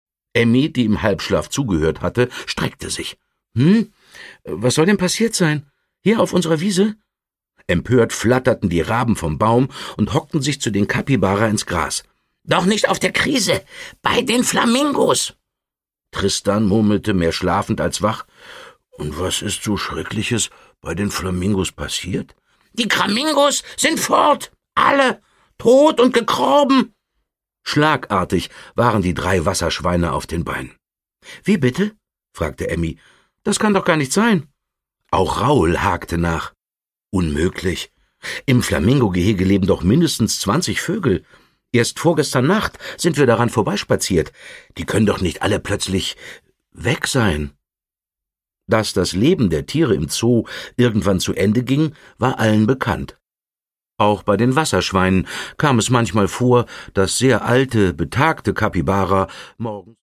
Produkttyp: Hörbuch-Download
Gelesen von: Dietmar Bär
Dietmar Bär nimmt es bei den Wasserschweinen stimmlich mit einem ganzen Zoo auf!